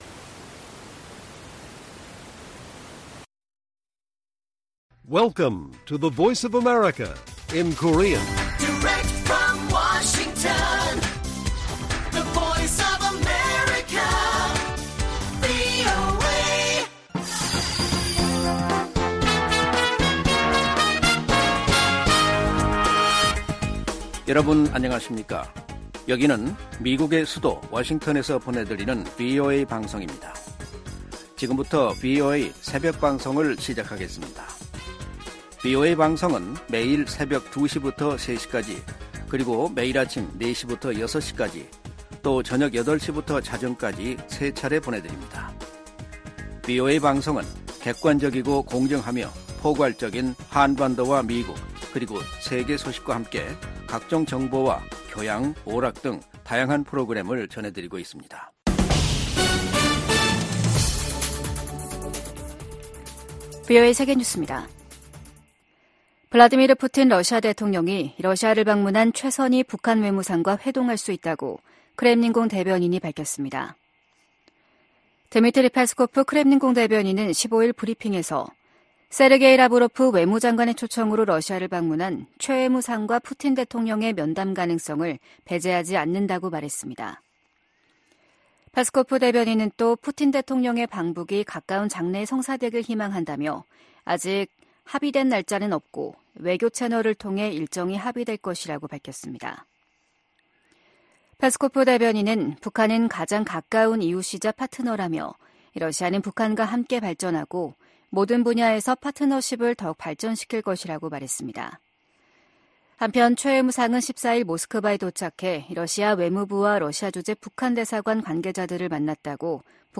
VOA 한국어 '출발 뉴스 쇼', 2024년 1월 16일 방송입니다. 북한은 신형 고체연료 추진체를 사용한 극초음속 중장거리 탄도미사일(IRBM) 시험 발사에 성공했다고 발표했습니다. 미국은 북한의 새해 첫 탄도미사일 발사를 유엔 안보리 결의 위반이라며 대화에 나설 것을 거듭 촉구했습니다. 중국 선박이 또다시 북한 선박으로 국제기구에 등록됐습니다.